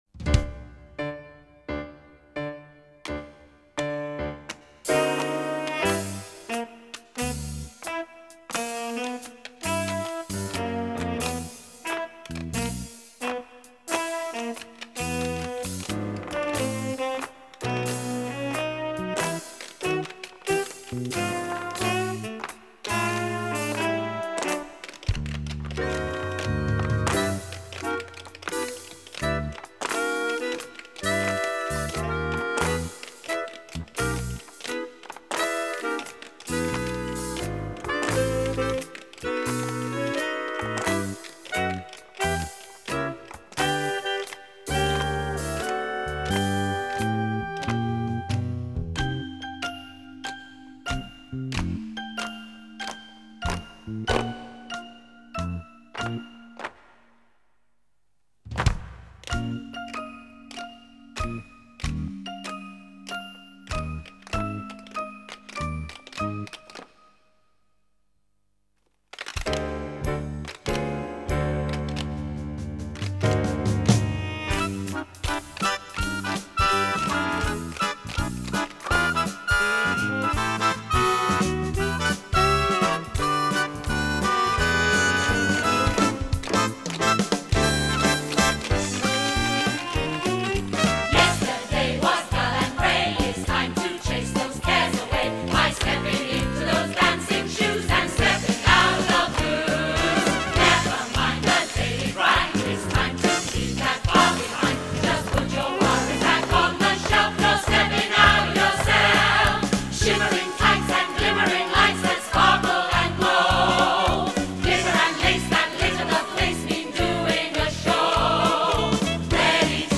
1996   Genre: Musical   Artists